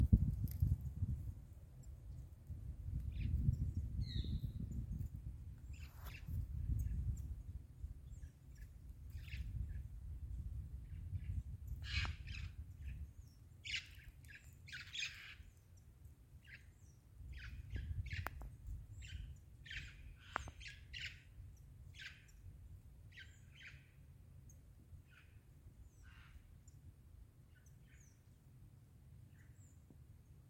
Loro Maitaca (Pionus maximiliani)
Nombre en inglés: Scaly-headed Parrot
Localidad o área protegida: Cerro San Javier
Condición: Silvestre
Certeza: Vocalización Grabada
loro-maitacamp3.mp3